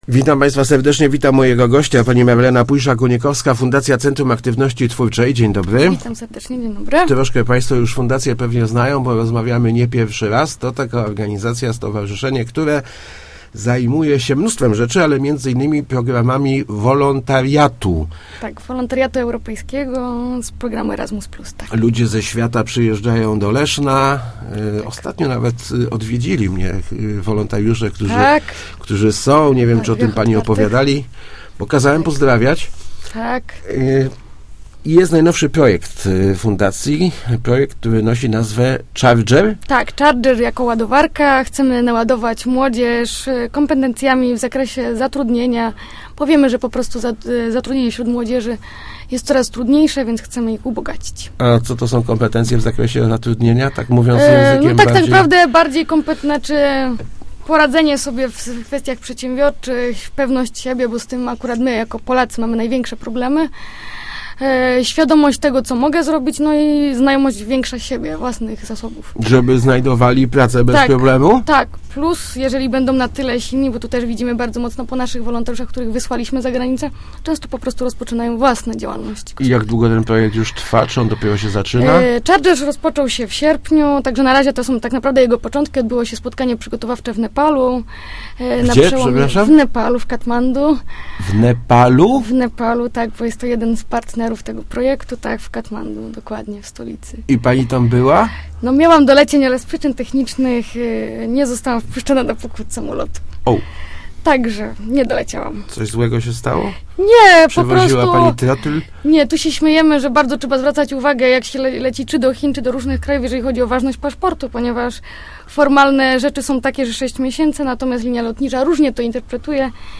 mówi�a w Rozmowach Elki